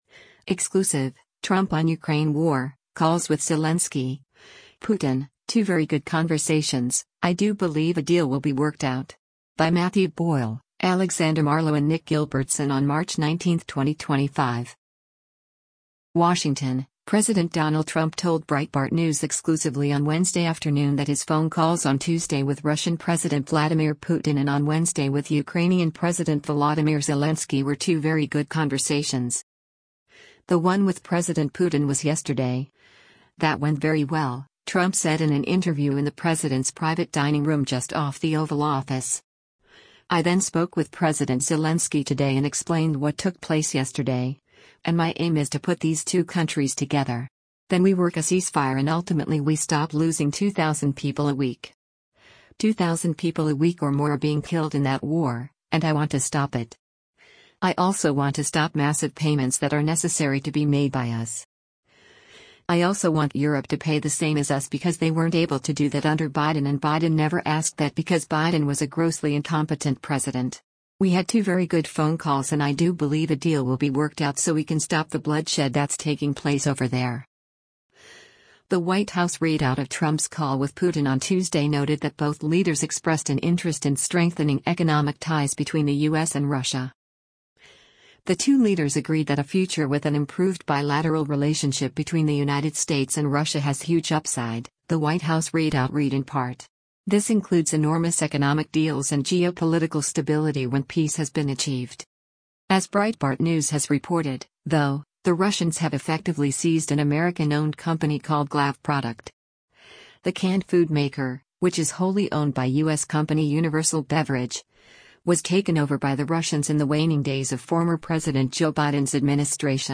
More from President Trump’s exclusive interview with Breitbart News at the White House is forthcoming.